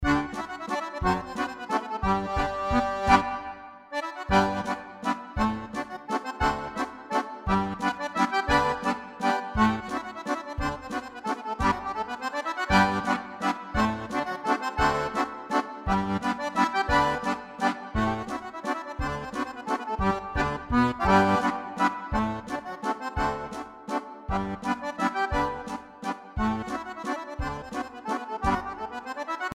Acordeó n